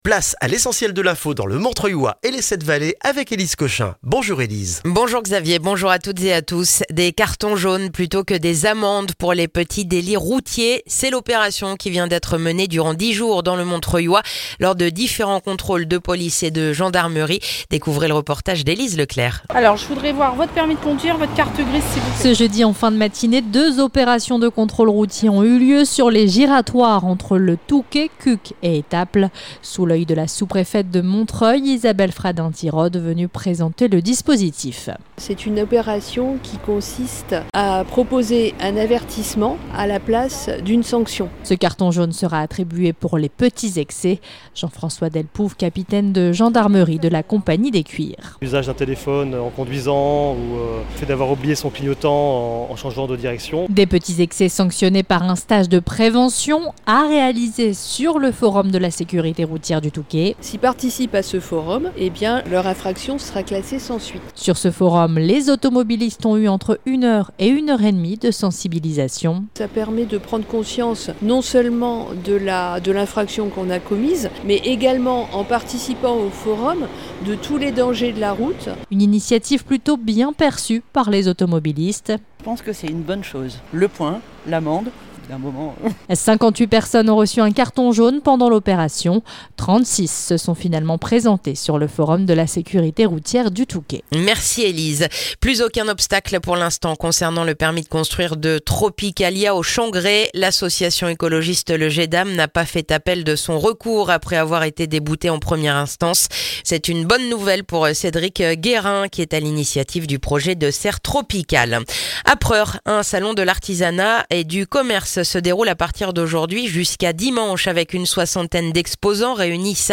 Le journal du vendredi 18 octobre dans le montrreuillois